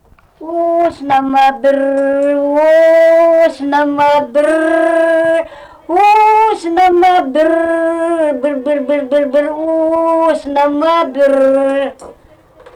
smulkieji žanrai
Jūžintai
vokalinis